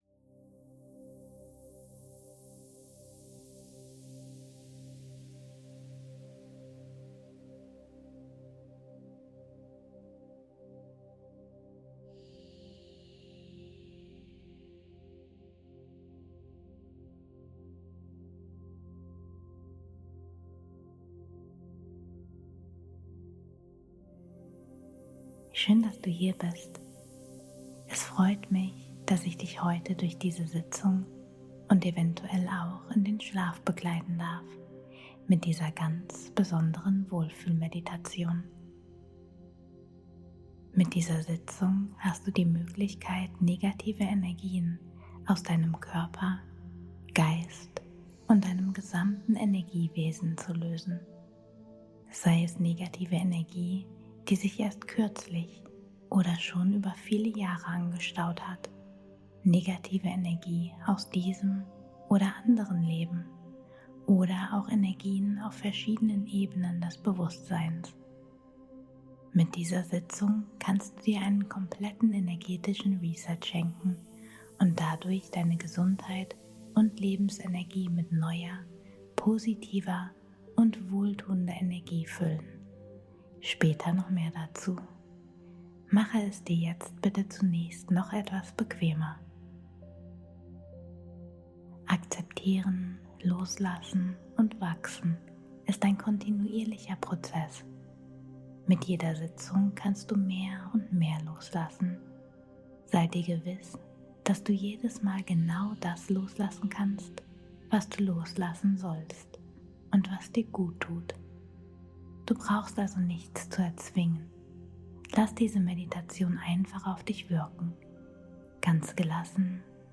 Sorgenfrei-Seelenfrieden - Geführte Meditation ins Traumland